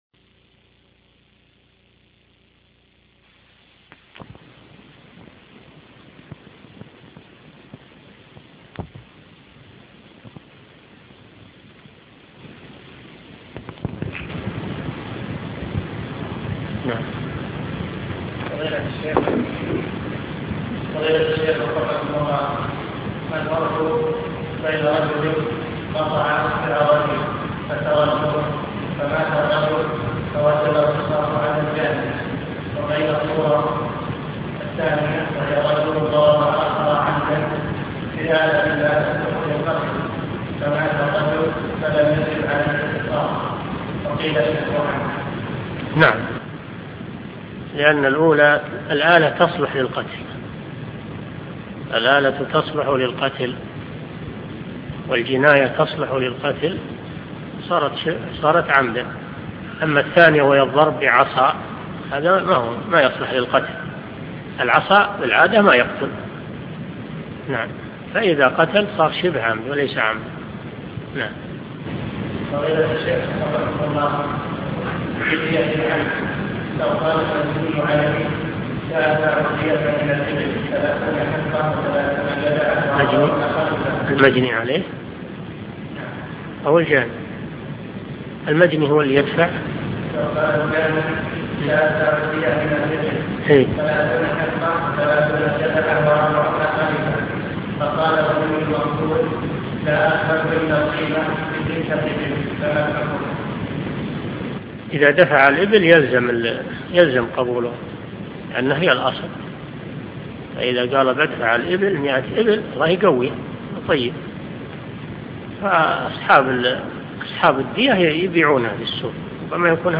عمدة الأحكام في معالم الحلال والحرام عن خير الأنام شرح الشيخ صالح بن فوزان الفوزان الدرس 69